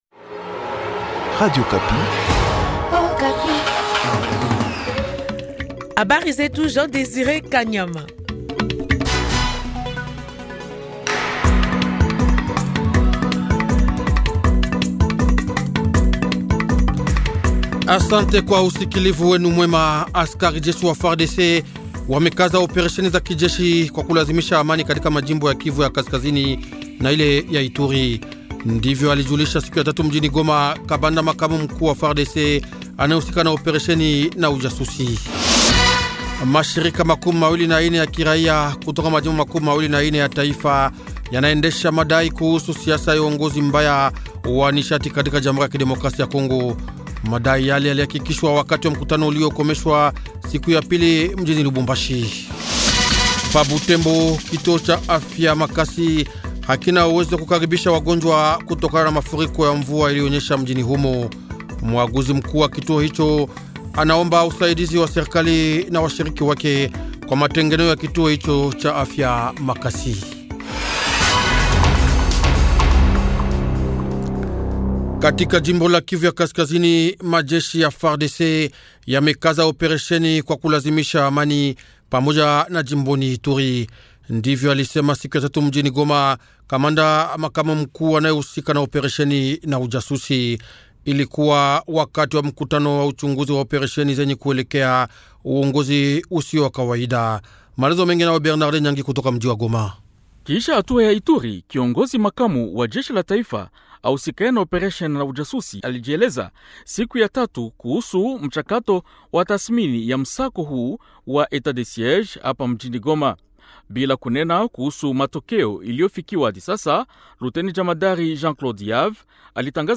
Journal Swahili Soir Jeudi 09/09/2021